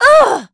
Valance-Vox_Damage_05.wav